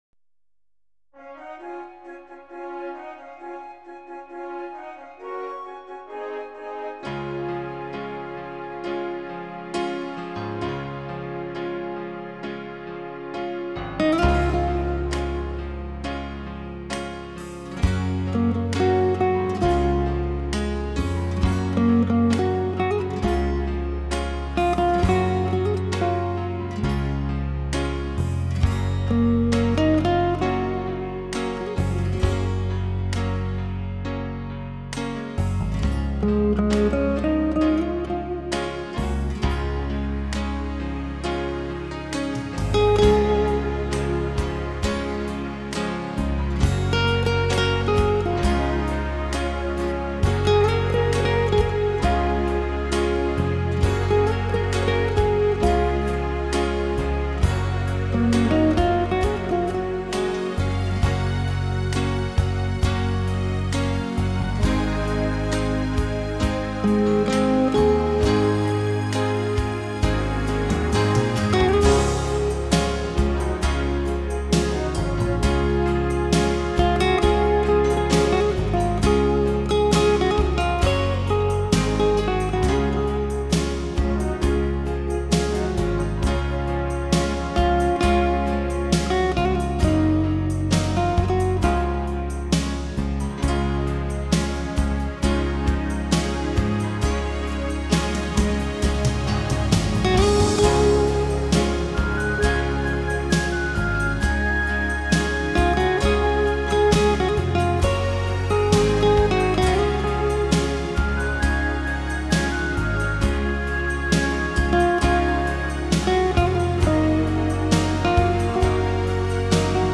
Выбирал оптимистичную музыку.